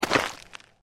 sporecarrier_foot_r01.mp3